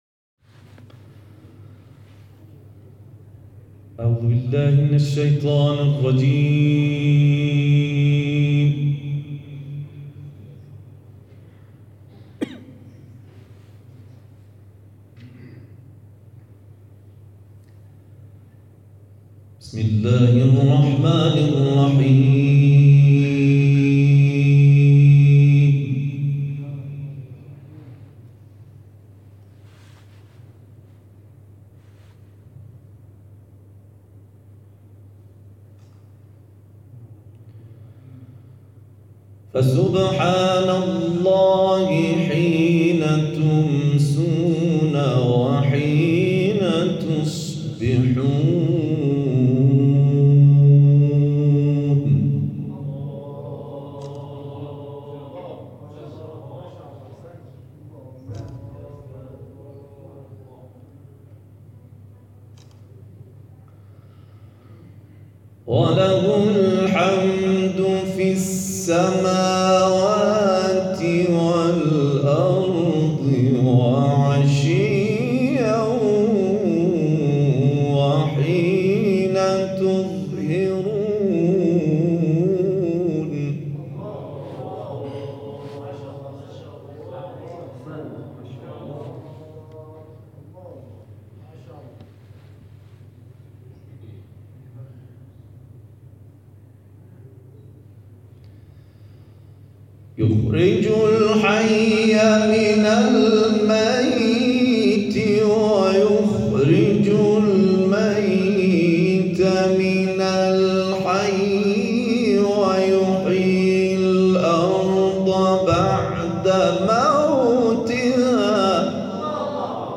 تلاوت
در سنندج